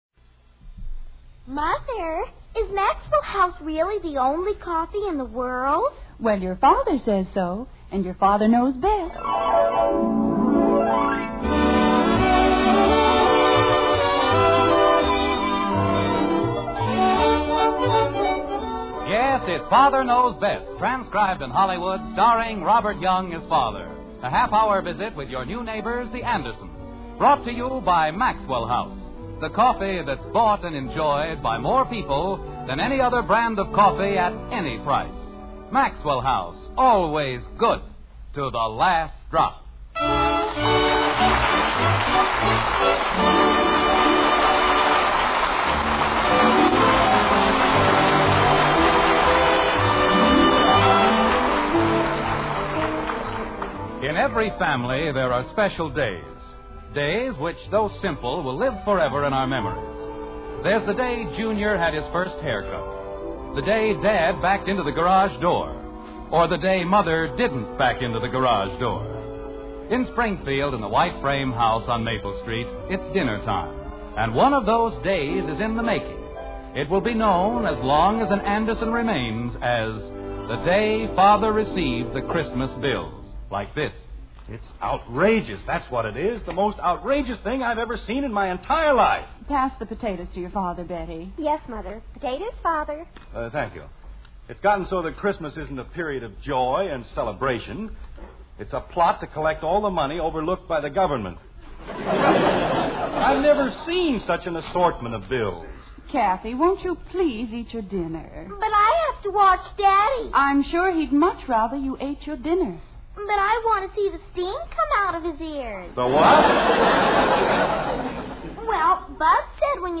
The Father Knows Best Radio Program